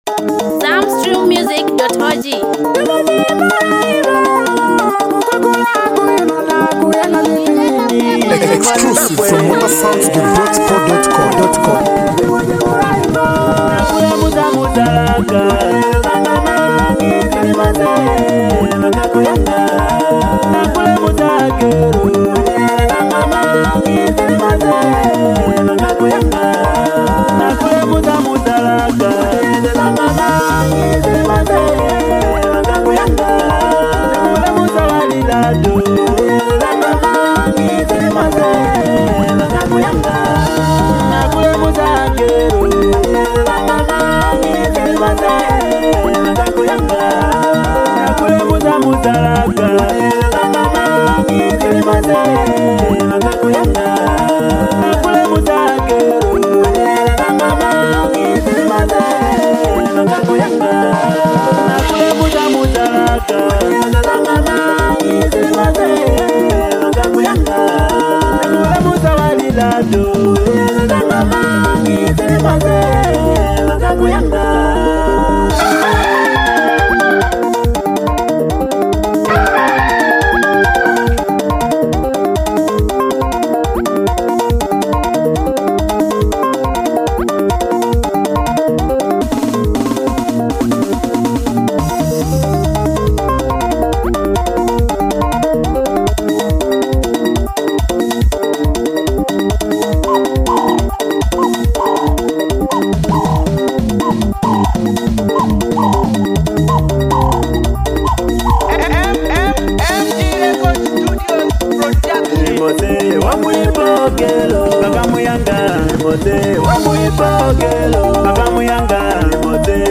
delivered in an energetic Zim Zim live style